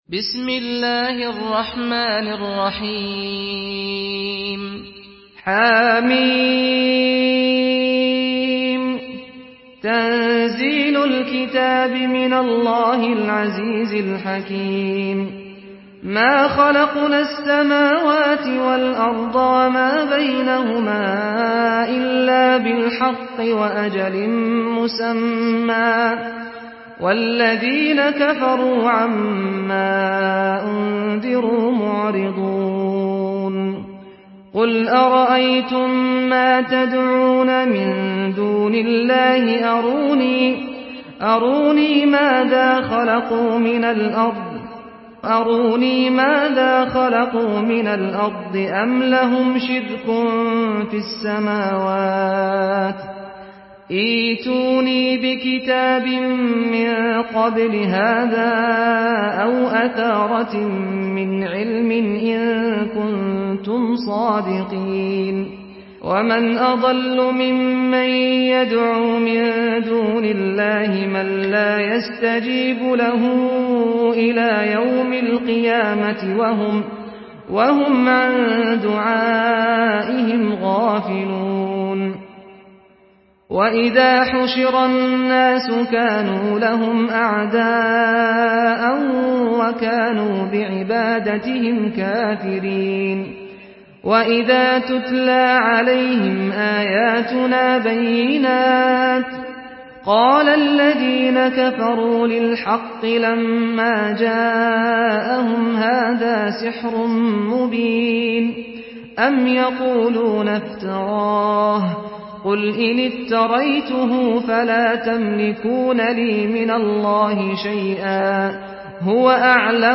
Surah Al-Ahqaf MP3 by Saad Al-Ghamdi in Hafs An Asim narration.
Murattal Hafs An Asim